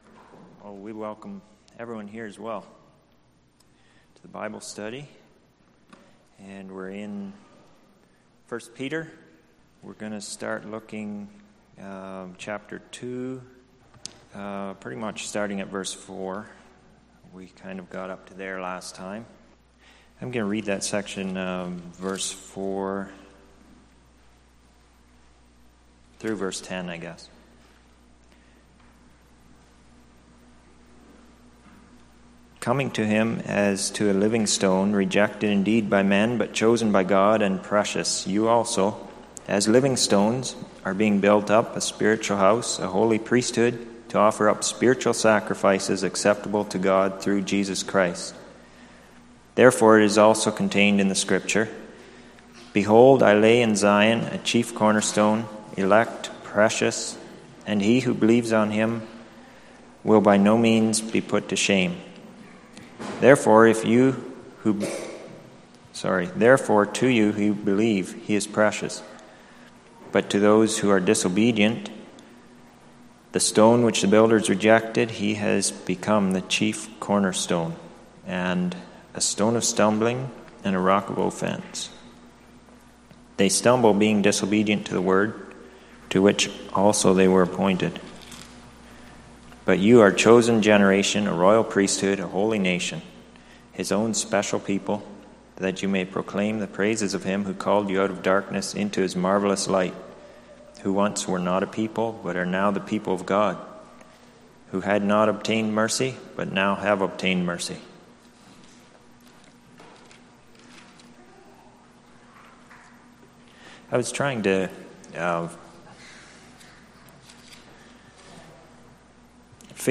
Sunday Morning Bible Study Service Type